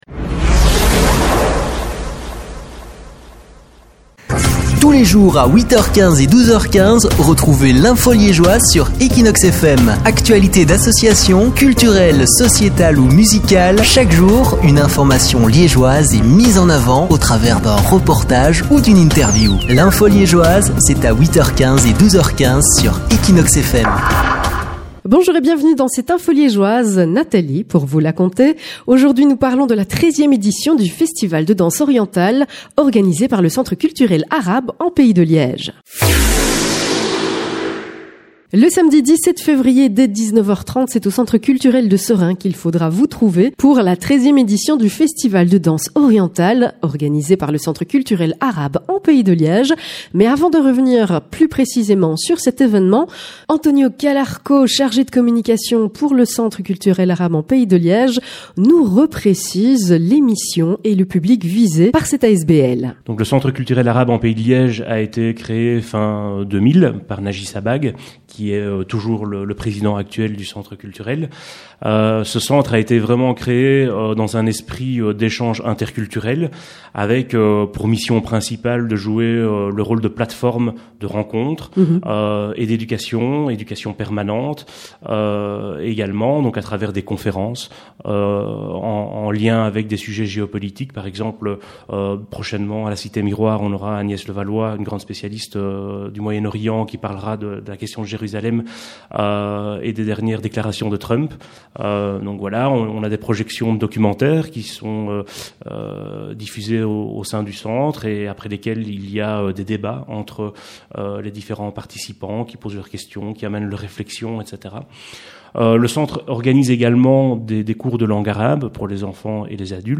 Équinoxe FM interviewe l’équipe du Centre culturel arabe en Pays de Liège à propos de notre 13e Festival de danses orientales
Merci à Equinoxe F.M. pour nous avoir reçu au sein de ses locaux le mercredi 31 janvier dernier, où nous avons parlé de notre 13e Festival de Danses orientales qui se tiendra le samedi 17 février 2018   au Centre culturel de Seraing